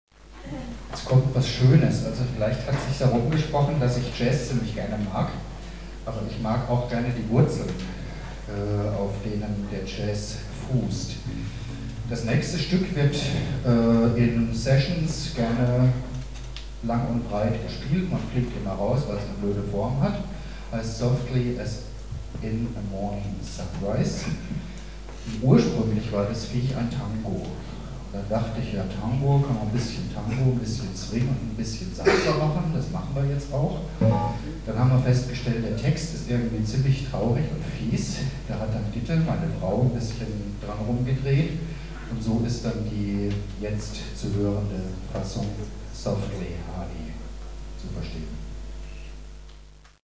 Konzert (Tufa, Trier)